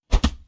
Close Combat Attack Sound 21.wav